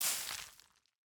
Minecraft Version Minecraft Version latest Latest Release | Latest Snapshot latest / assets / minecraft / sounds / item / bonemeal / bonemeal2.ogg Compare With Compare With Latest Release | Latest Snapshot
bonemeal2.ogg